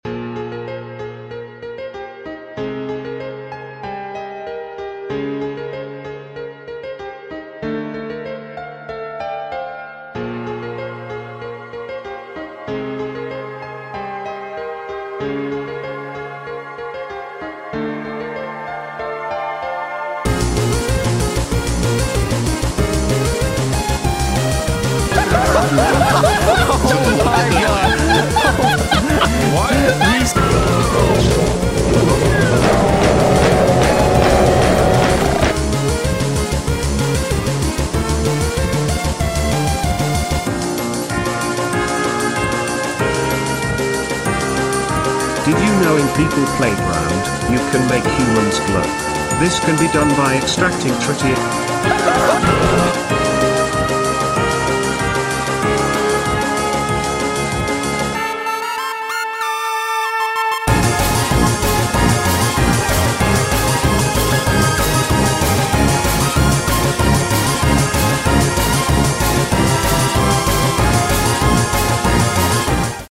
if the qualitys low its cuz of tiktok